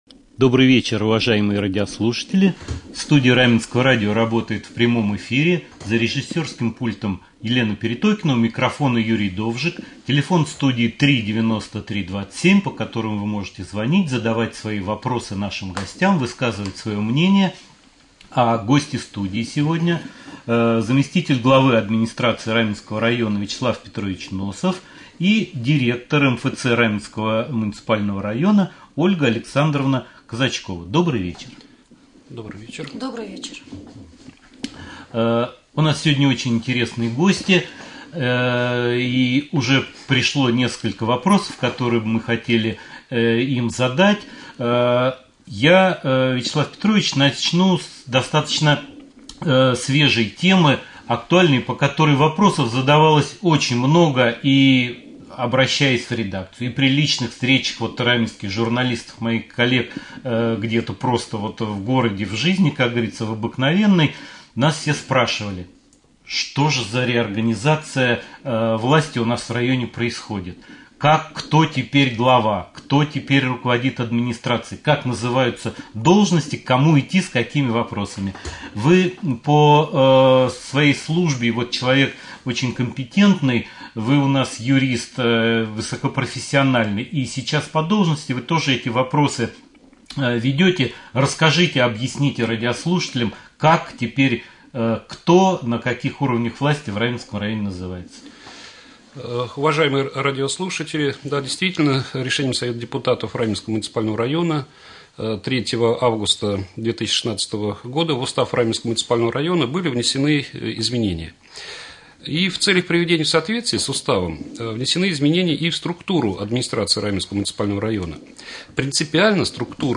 Прямой эфир.